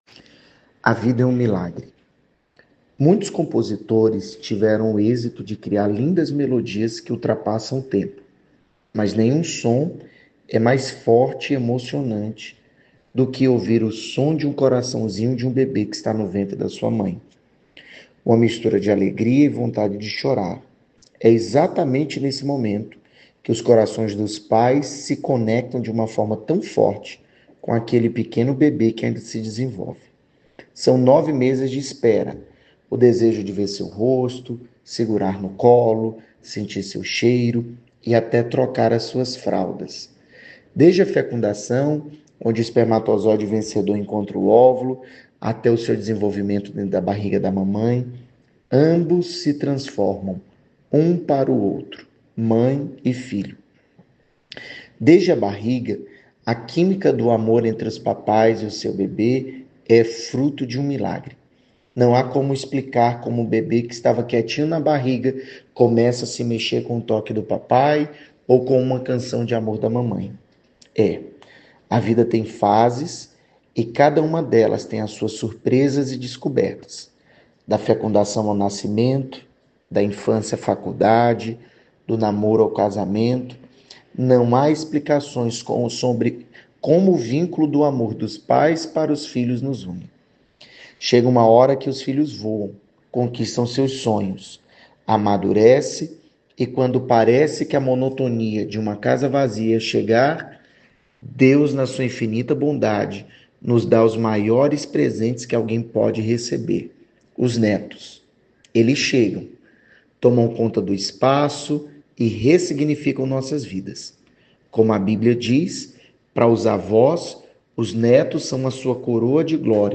Essa voz precisa ser forte e madura… assemelhando-se a um texto motivacional. Com paradas, e entonações necessárias